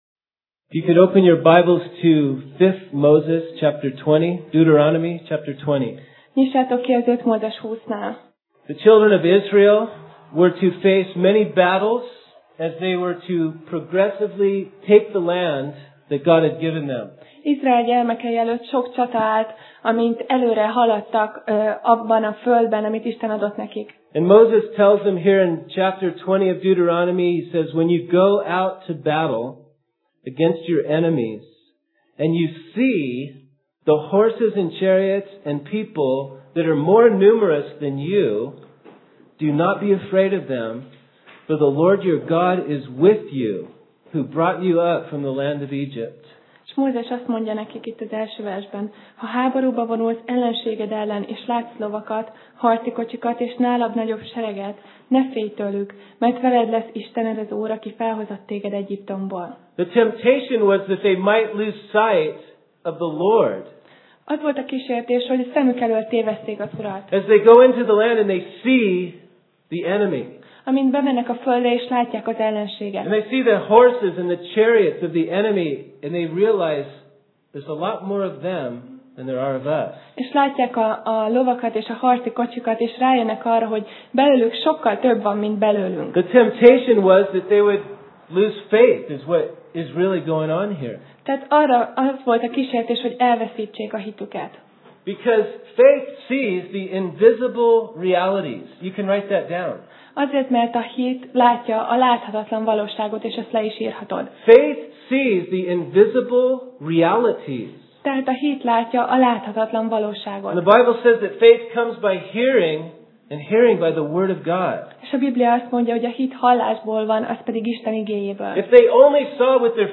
5Mózes Passage: 5Mózes (Deut) 20:1-20 Alkalom: Szerda Este